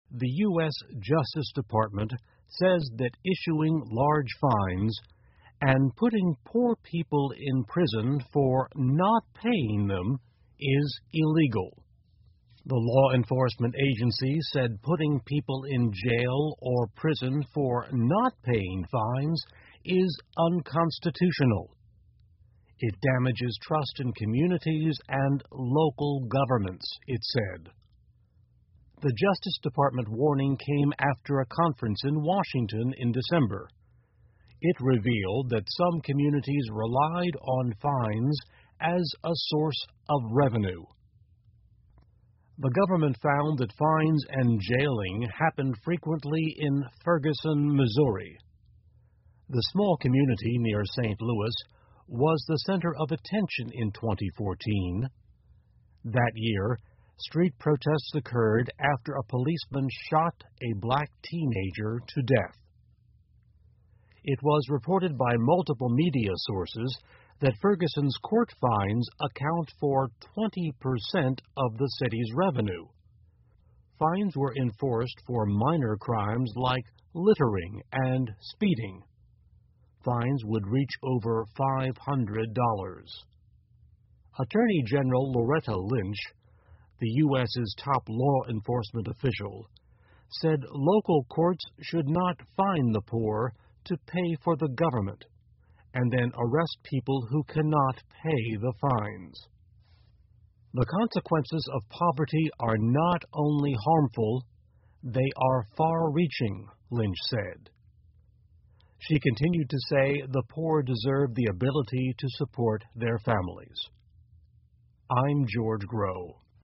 VOA慢速英语2016 美国警告对穷人罚款这一行为 听力文件下载—在线英语听力室